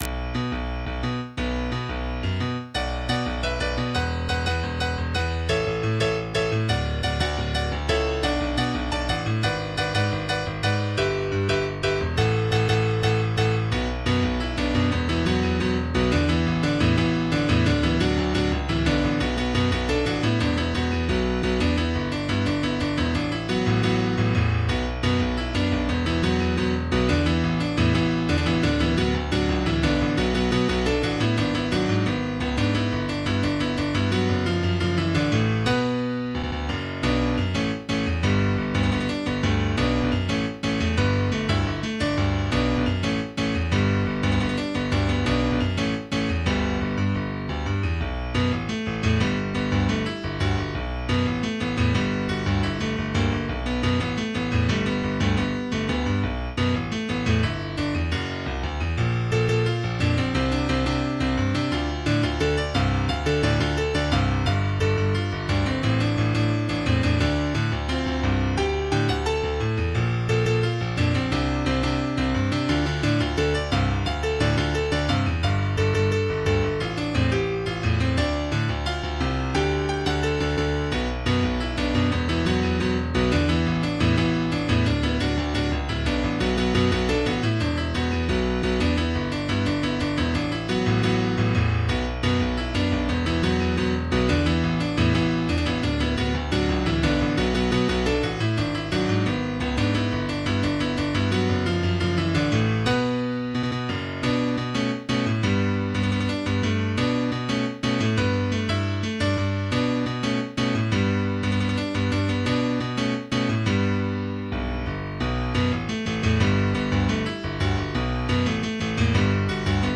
MIDI 28.01 KB MP3